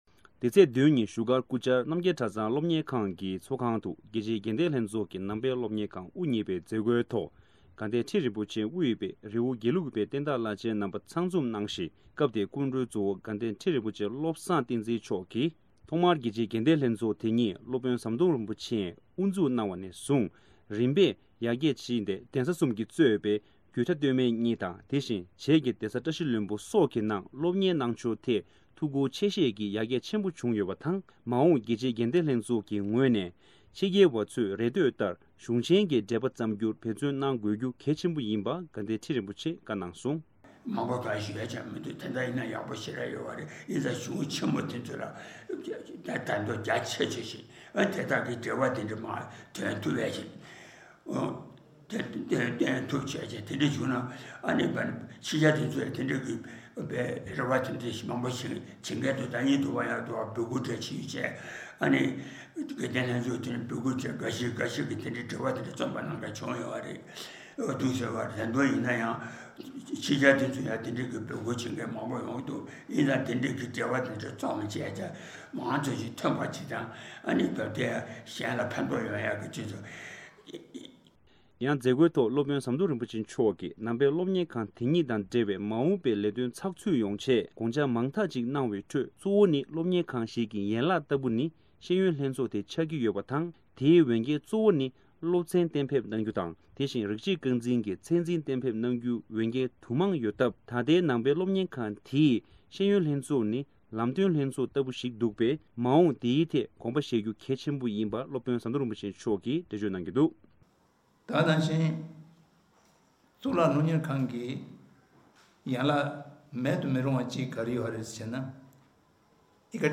རྒྱལ་སྤྱིའི་དགེ་ལྡན་ལྷན་ཚོགས་ཀྱི་ངོས་ནས་ཕྱི་རྒྱལ་བའི་རེ་འདོད་ལྟར་གཞུང་ཆེན་གྱི་འགྲེལ་པ་བརྩམས་རྒྱུར་འབད་དགོས་སྐོར་དགའ་ལྡན་ཁྲི་རིན་པོ་ཆེས་གསུངས་པ།